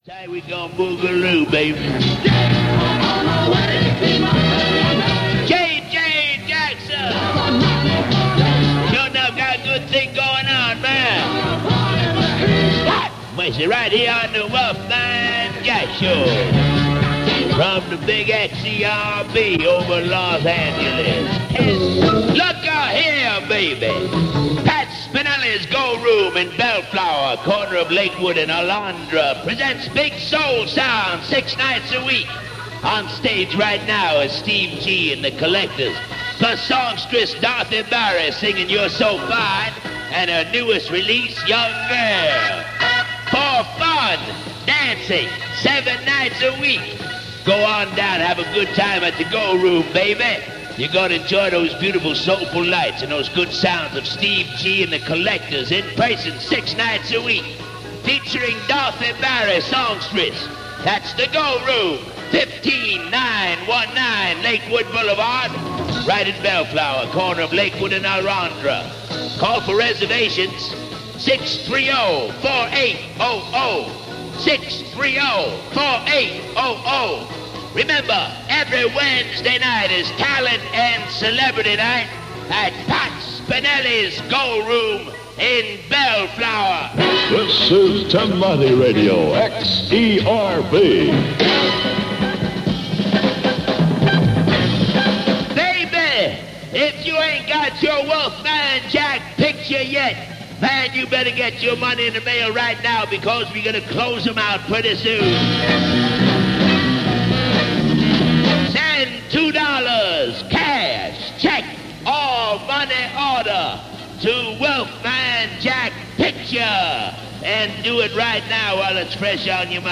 He had that kind of voice and everybody wanted to sound just like him.
The music was great, and it was all over the map, but Wolfman Jack was always talking over it, so you couldn’t really spend much time listening to a song you liked before a nicotine-stained nasal shriek shot out of the air and straight into your psyche – it was him, letting you know where you were.